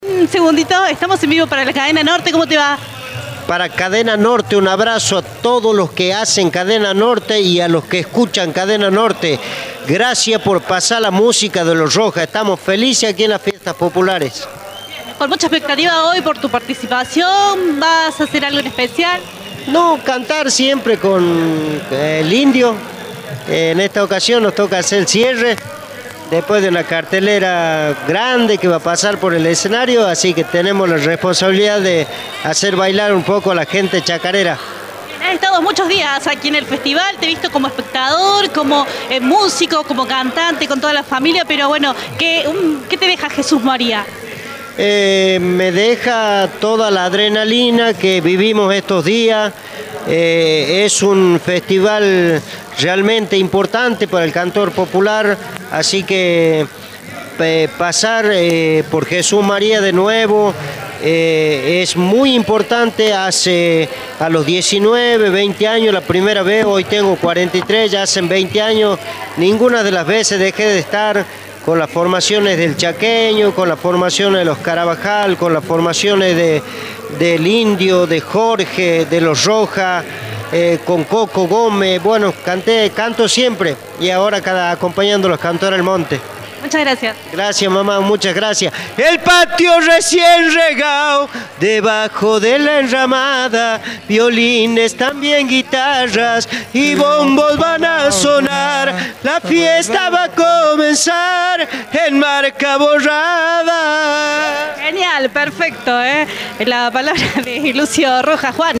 En la noche final del festival, hablamos con cada uno de los protagonistas, los consagrados, autoridades y artistas que pasaron por la última noche de color y coraje.